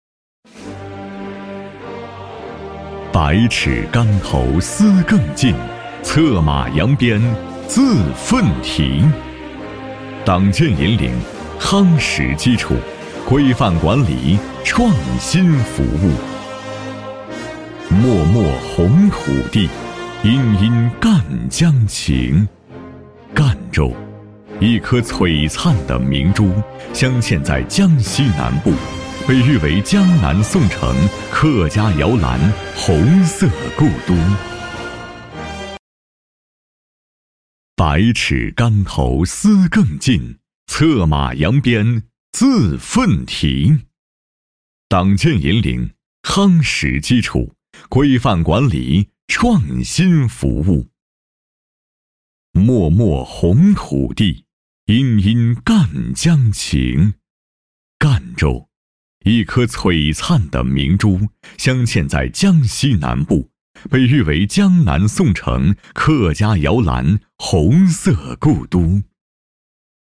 Échantillons de voix natifs
Annonces politiques
Clean audio with no breaths or mouth noises
Styles: From conversational bright and friendly, to warm and authoritative.
Sennheiser MKH 416 Mic, UA Volt 276 Interface, Pro Recording Booth, Reaper
BarytonBasse
AutoritaireAmicalEngageantÉnergique